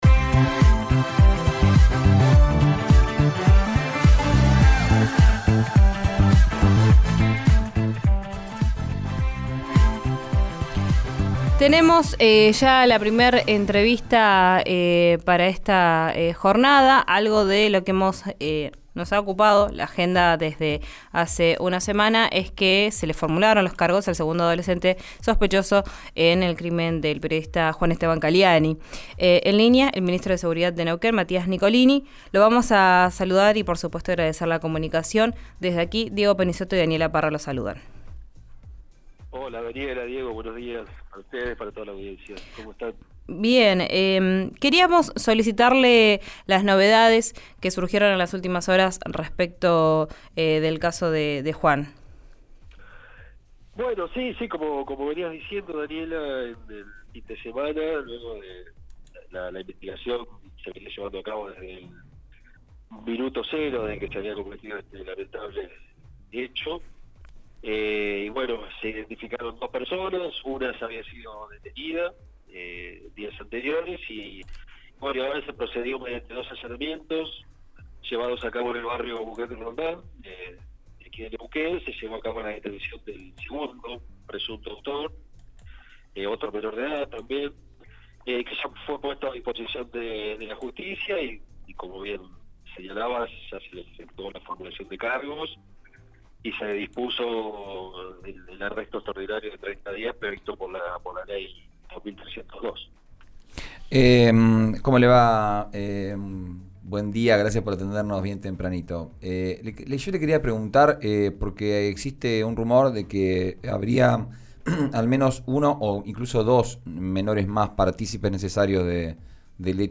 El ministro de Seguridad de Neuquén habló con RIO NEGRO RADIO acerca de la investigación por el asesinato del periodista en Neuquén y aseguró que hay 'distintos hilos investigativos'.
Escuchá al ministro de Seguridad de Neuquén, Matías Nicolini, en RIO NEGRO RADIO